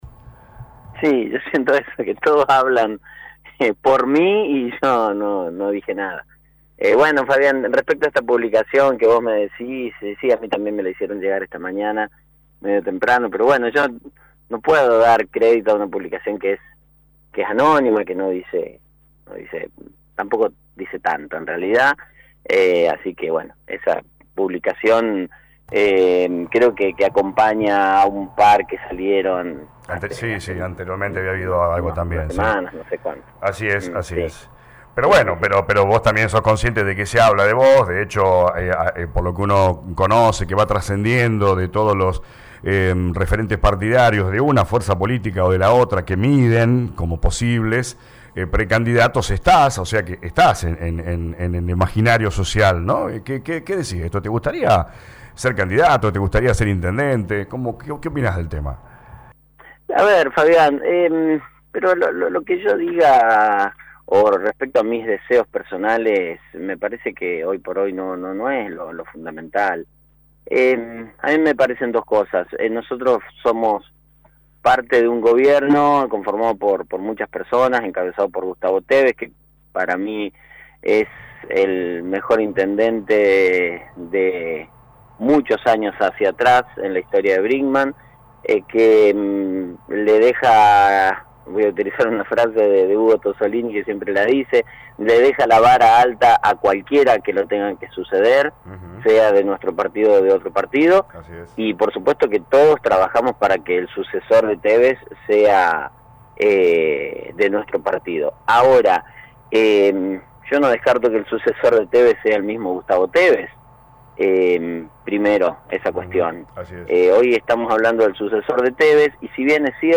El actual concejal Lic. Mauricio Actis habló con LA RADIO 102.9 haciendo una valoración de la gestión de Tevez y asegurando que hoy la gente quiere que se le solucionen los problemas y no anda pensando en quien será candidato.